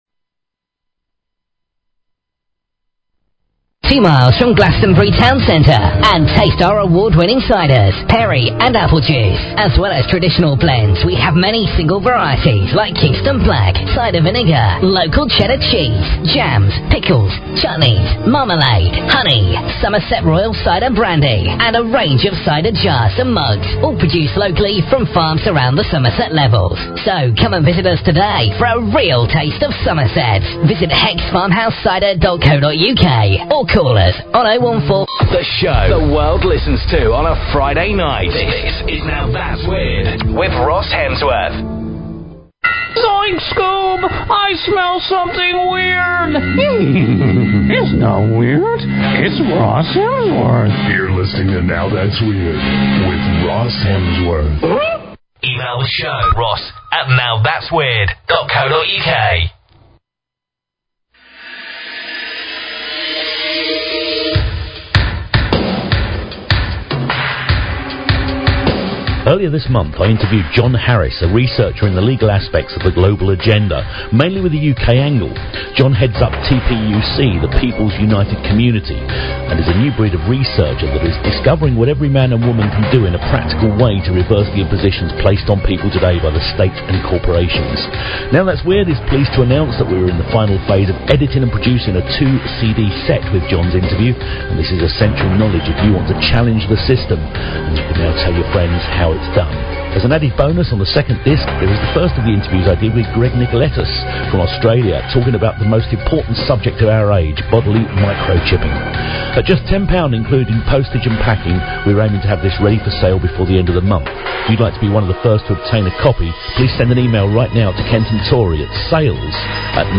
Show Headline Now_Thats_Weird Show Sub Headline Courtesy of BBS Radio Now Thats Weird - September 18, 2009 Now Thats Weird Please consider subscribing to this talk show.